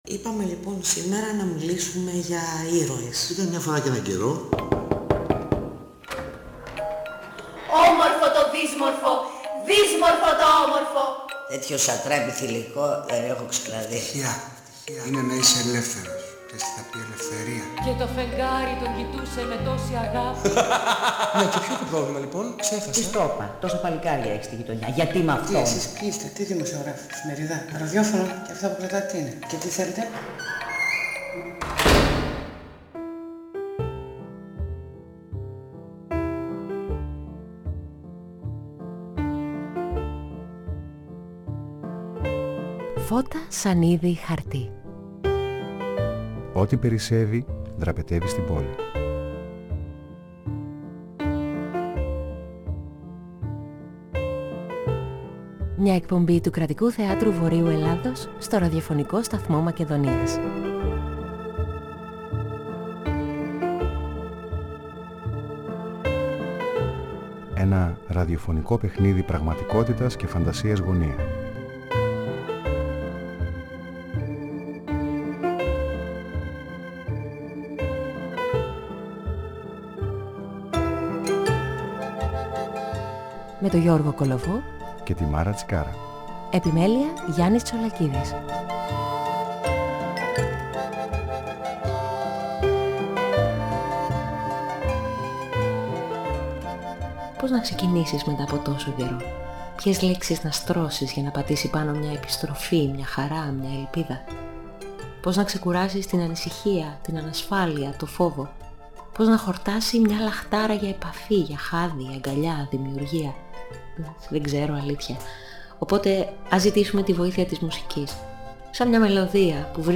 Ιστορίες καραντίνας -και όχι μόνο- μας αφηγείται τηλεφωνικά